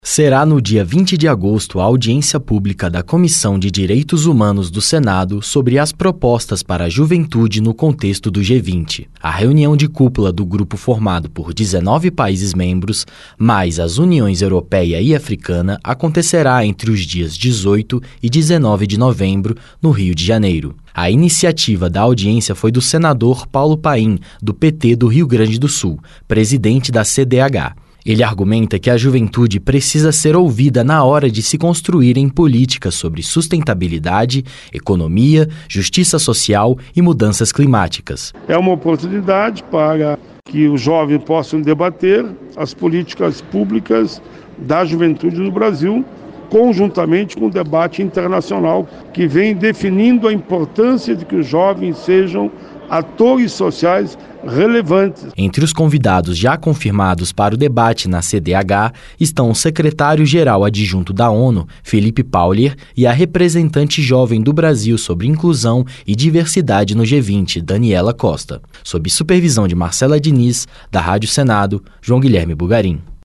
Transcrição